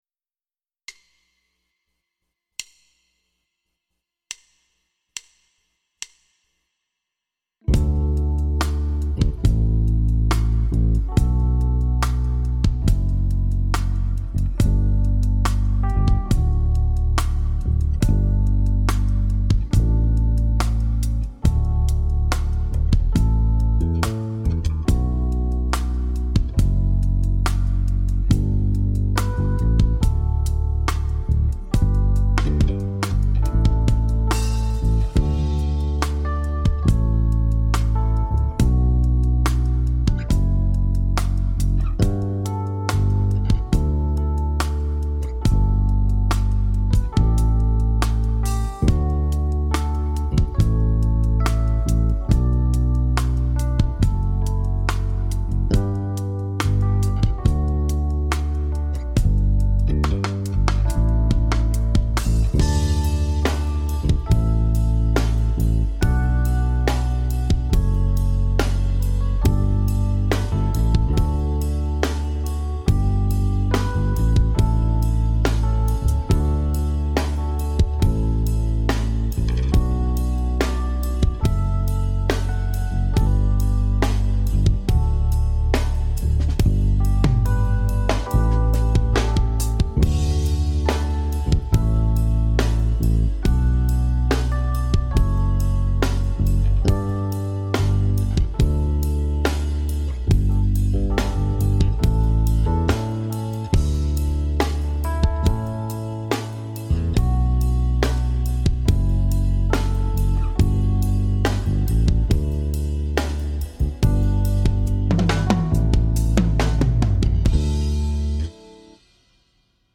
伴奏帶：
canonACMP_70bpm.mp3